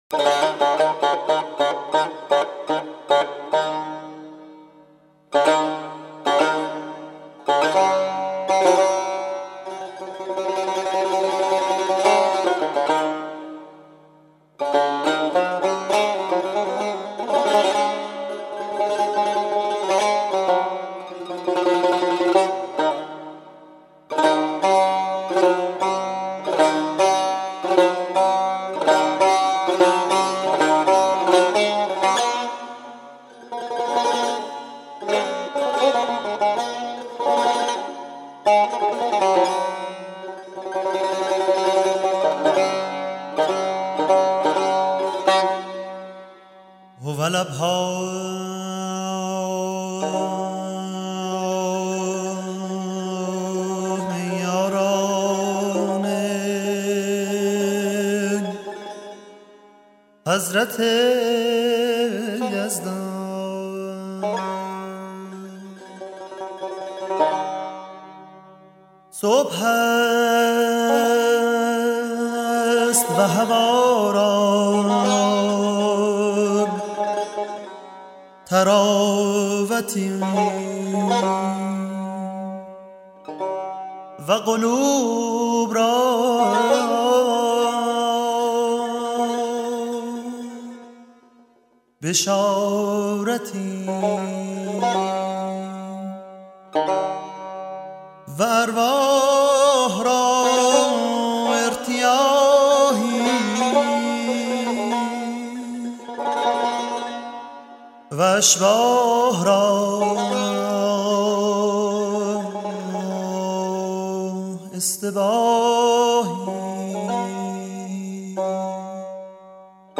6. لوح مبارک حضرت عبدالبهاء ( چهارگاه )
قند پارسی لحن فارسی